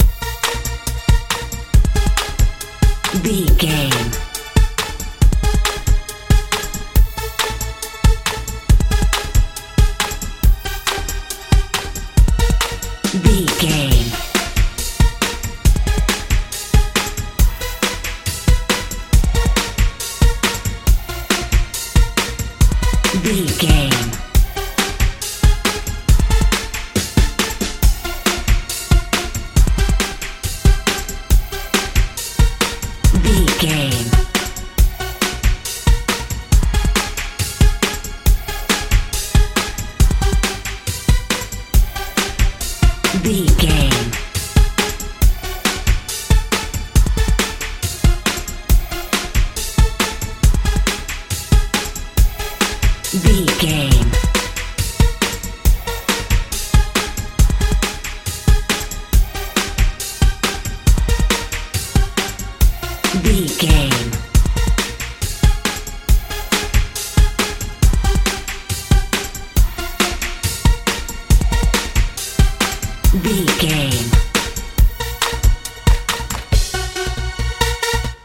techno hip hop feel
Ionian/Major
B♭
energetic
bouncy
synthesiser
bass guitar
drums
80s
90s
suspense